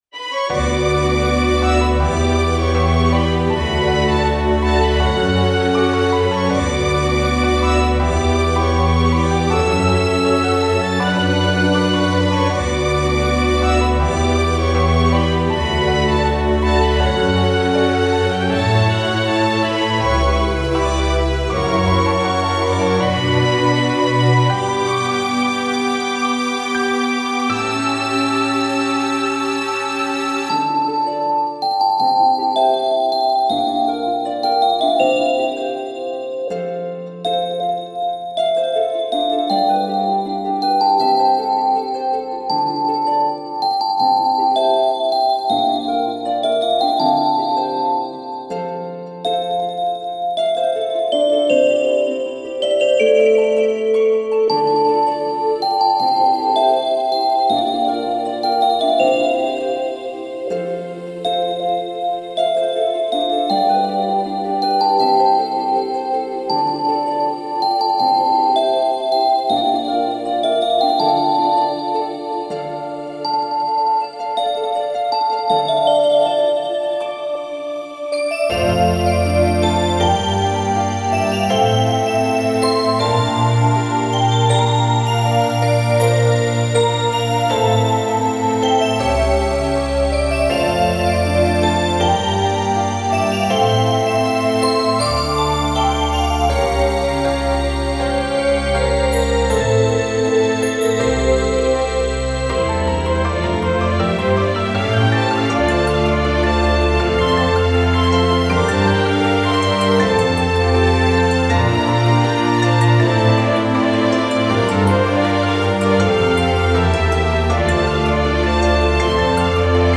フリーBGM イベントシーン 幻想的・神秘的
フェードアウト版のmp3を、こちらのページにて無料で配布しています。